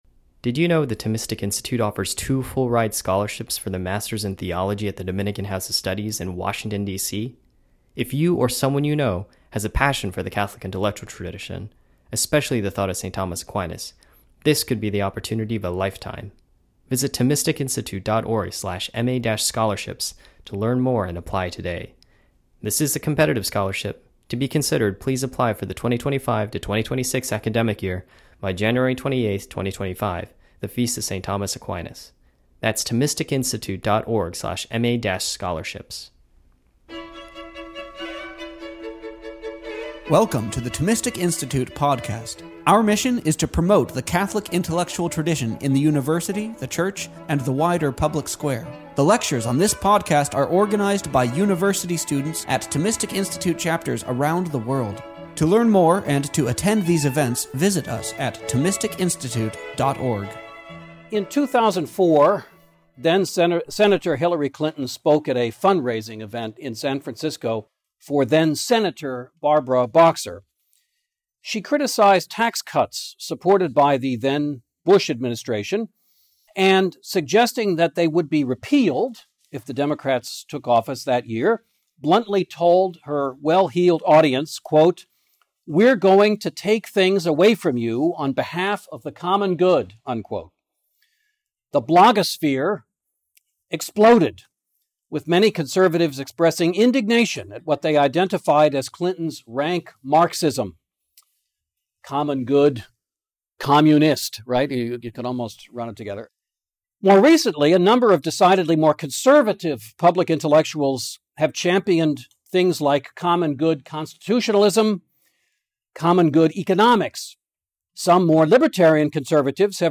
This lecture was given on October 1st, 2024, at Indiana University.